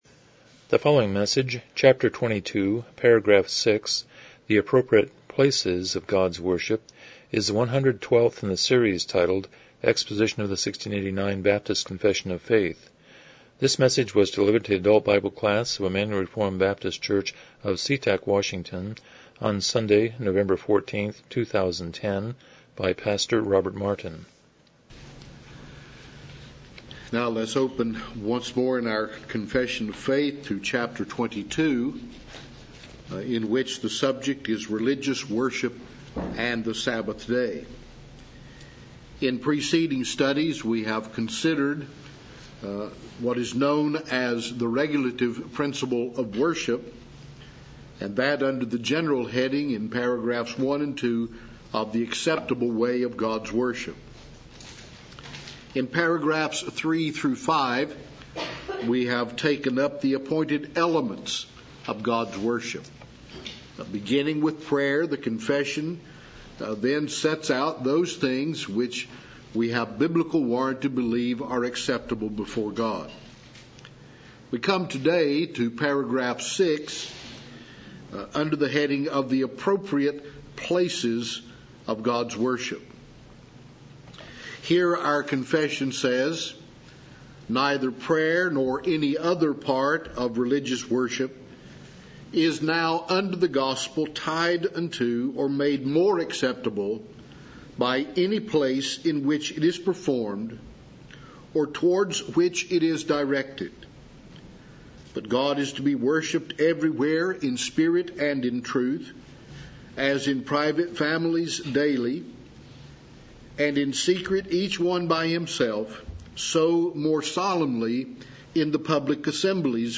Series: 1689 Confession of Faith Service Type: Sunday School « Psalm 103:1-5